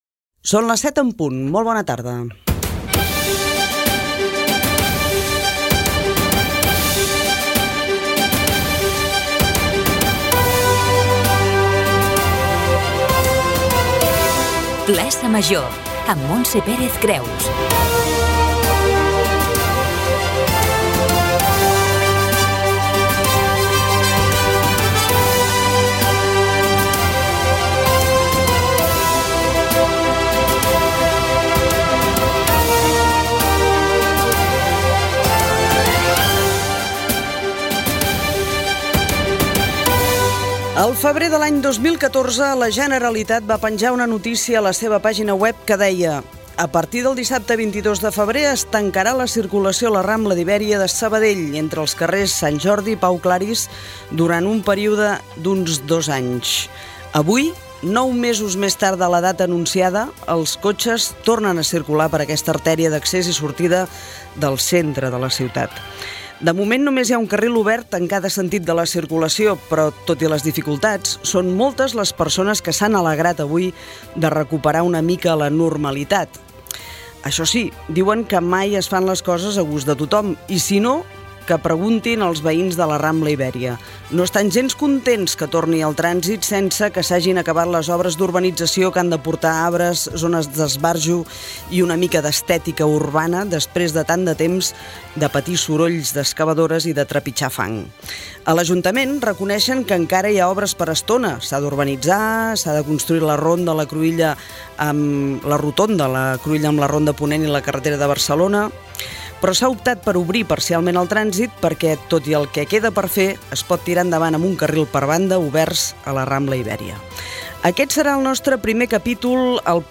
Hora, careta del programa, circulaciói obres a la Rambla Ibèria de Sabadell, sumari informatiu, presentació dels tres invitats que intervindran, indicatiu del programa, "El so del dia", el temps
Informatiu
FM